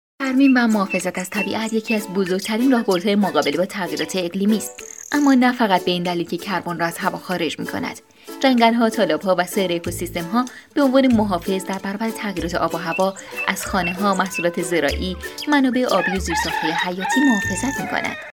Female
Young
Commercial